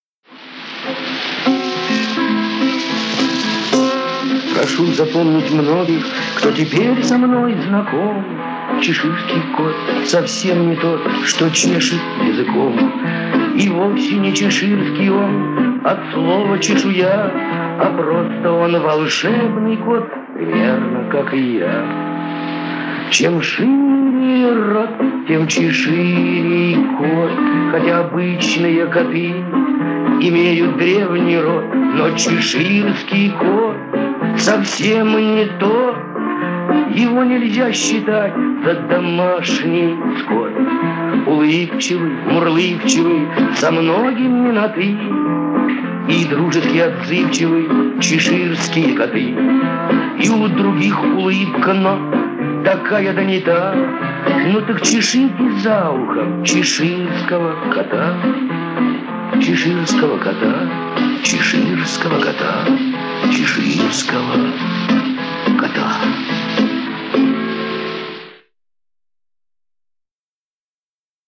музыка, слова и исполнение